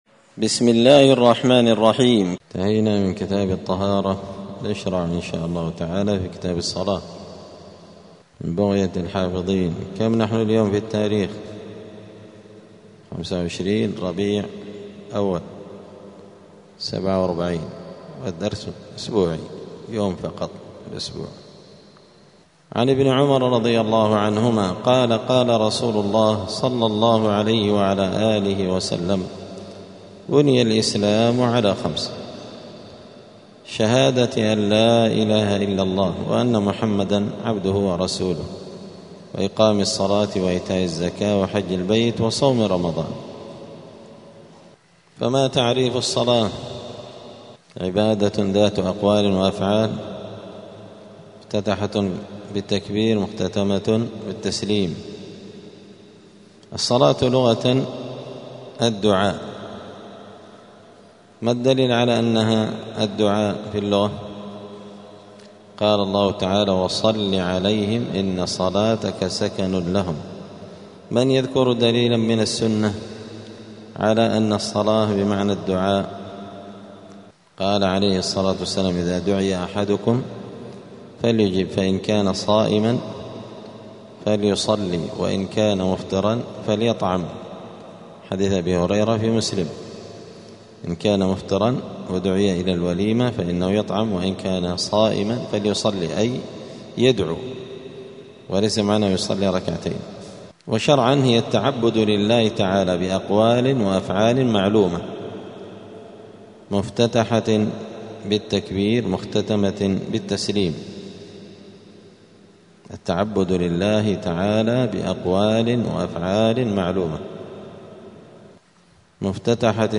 دار الحديث السلفية بمسجد الفرقان قشن المهرة اليمن
*الدرس العشرون بعد المائة [120] {تعريف الصلاة ووقت فرضيتها}*